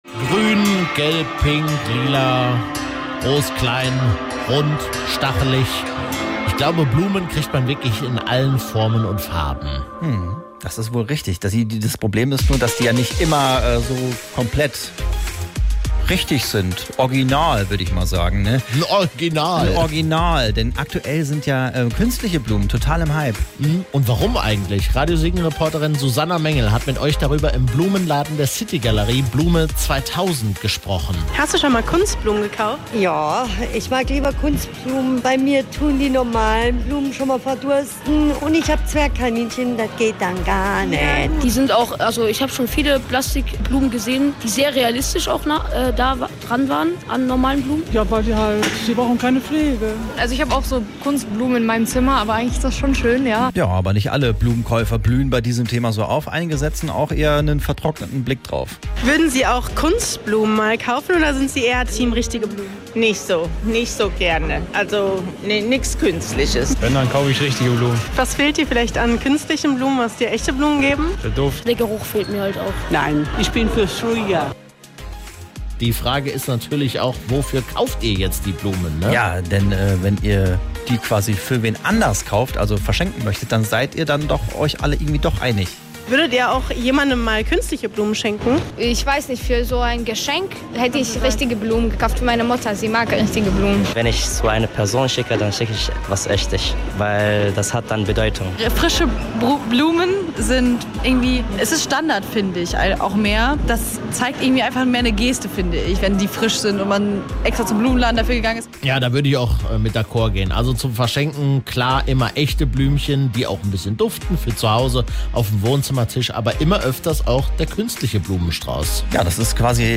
Siegenerinnen und Siegener